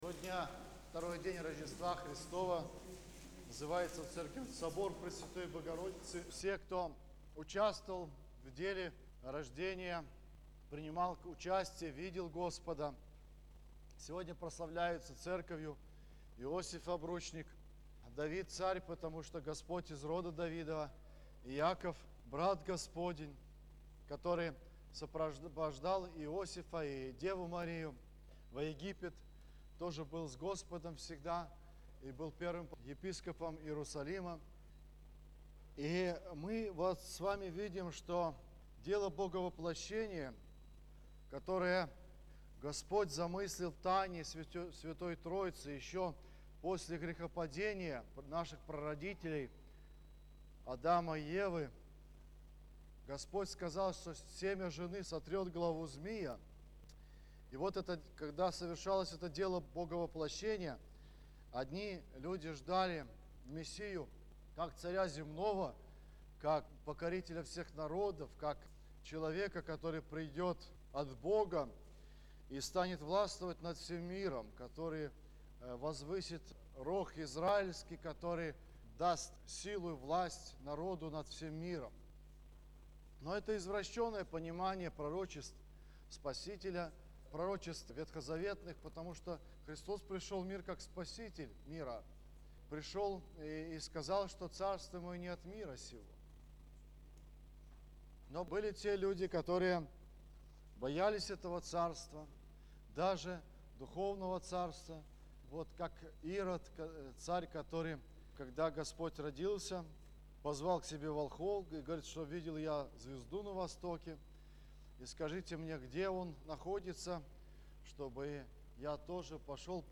Воскресная проповедь